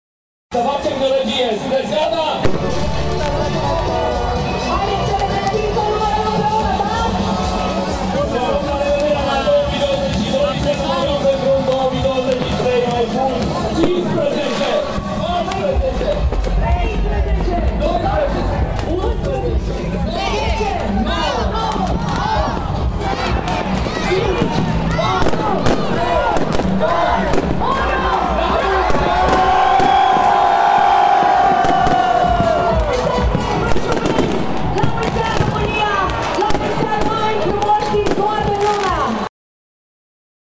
Mii de brașoveni și turiști au ales să petreacă Revelionul în Piața Sfatului
Piața Sfatului a fost plină ochi de spectatori și deja, în ultimul sfert de oră nu se mai putea înainta prin mulțimea de oameni.
AMBIANTA-NEW-YEAR-BRASOV.mp3